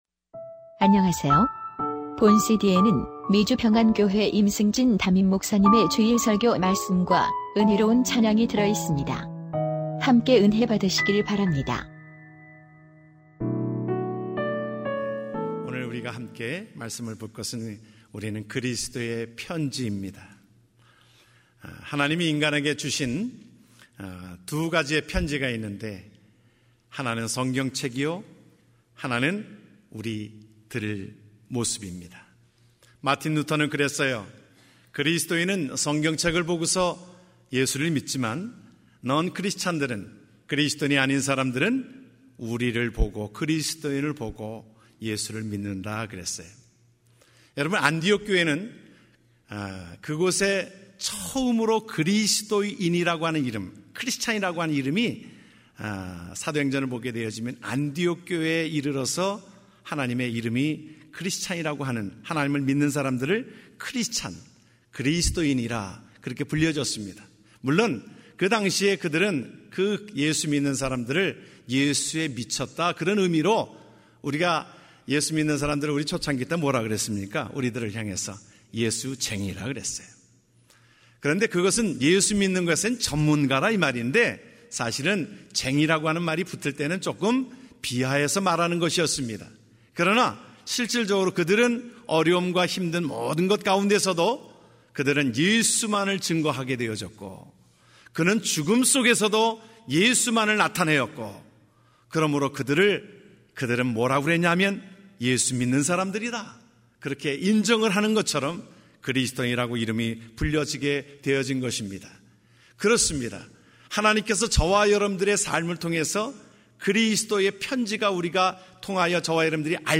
2014년 11월 9일 주일3부 예배 설교 말씀: 우리는 그리스도의 편지입니다. (고후 3:2-5)